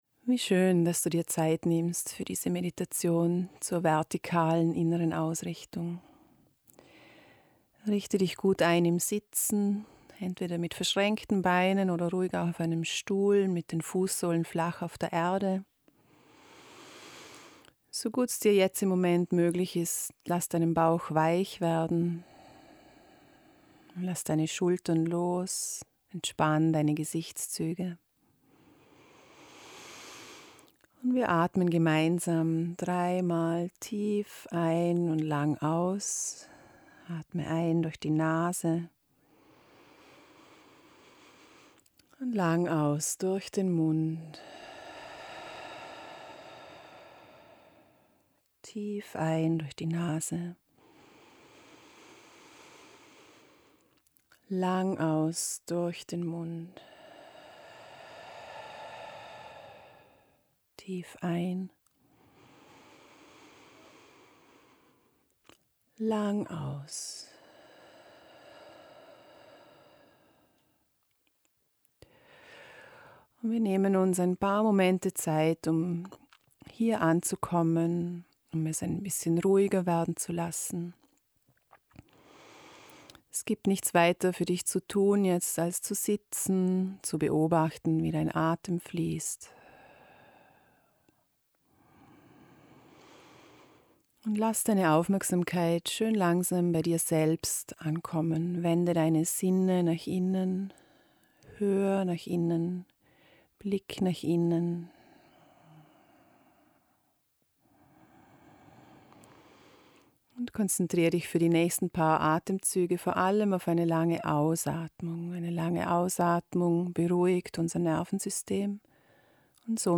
Tu Dir Gutes und gönn‘ Dir eine 20-minütige Meditation, die deine innere Zentrierung stärkt und Deine Erdung & Balance fördert.